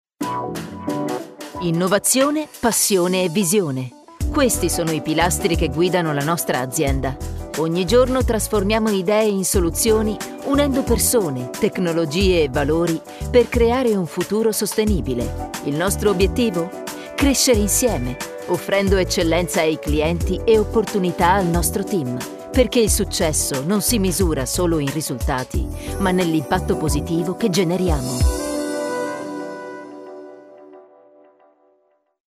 Ti offriamo i migliori speaker, quelli senza difetti di pronuncia, dizione e impostazione, che hanno superato ogni esame. Pochi, ma buoni. Anzi, ottimi!
Speaker professionista
Demo-professionale-home-page-montato.mp3